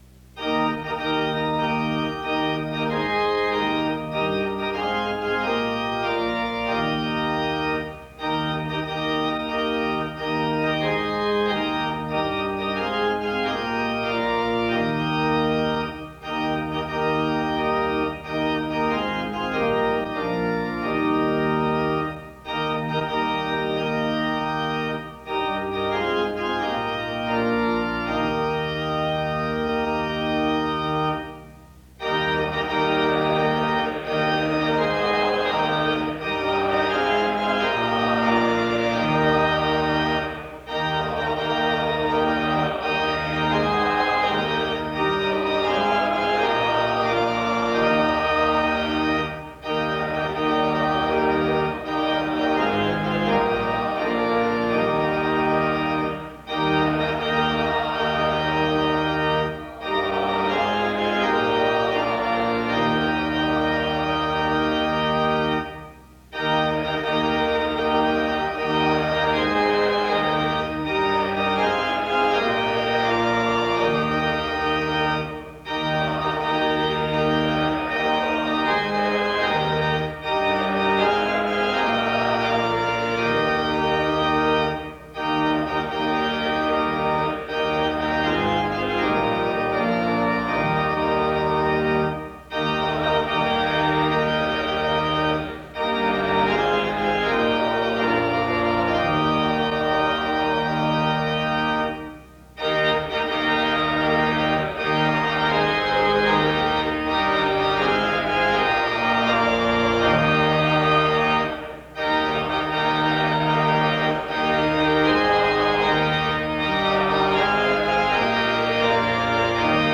Opening music begins the service from 0:00-2:25. A prayer is offered from 2:44-3:32. A couple of announcements are given from 3:37-4:21.